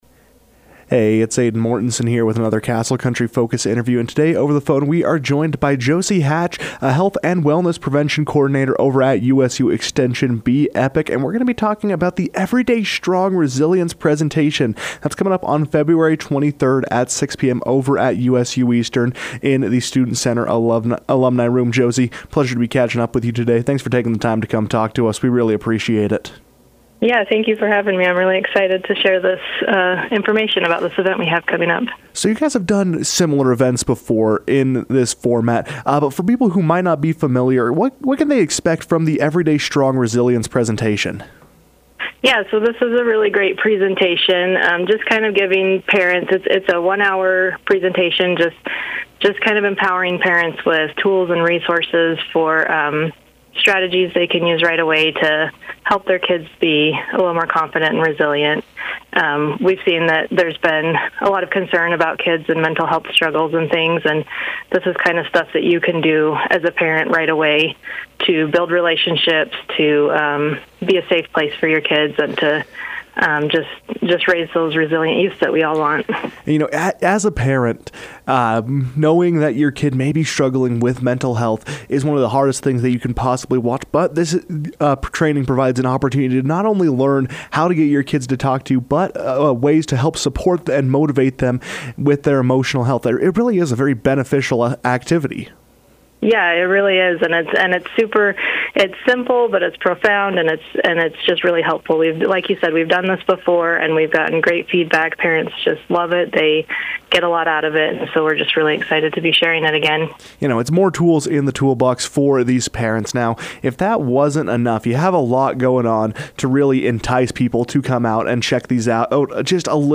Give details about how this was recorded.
USU Extension Be Epic plans Everyday Strong Resilience Training for parents Feb. 23 | KOAl - Price,UT